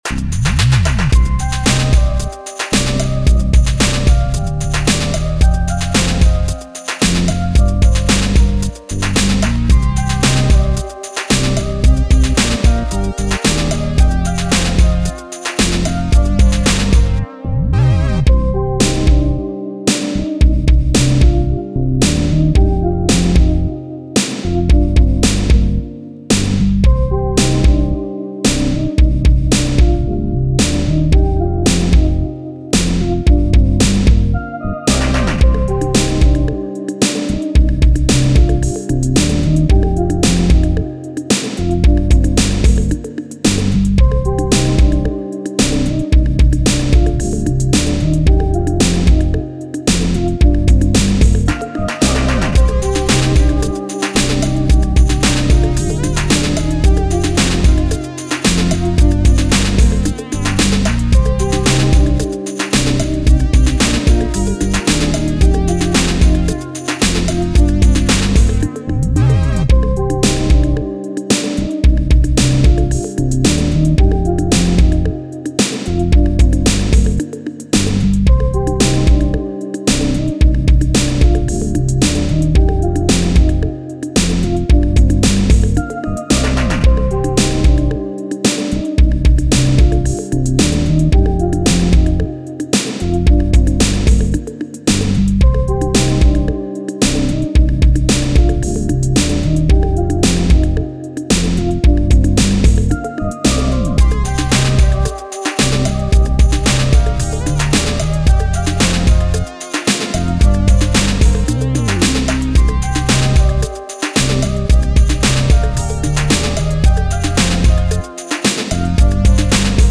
R&B, 80s, 90s
Cmin